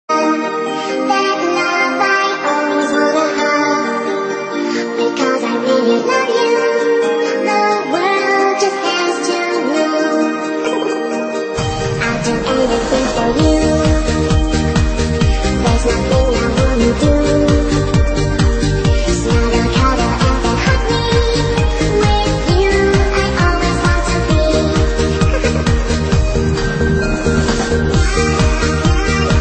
• Funny Ringtones